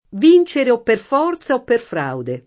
v&n©ere o pper f0rZa o pper fr#ude] (Machiavelli)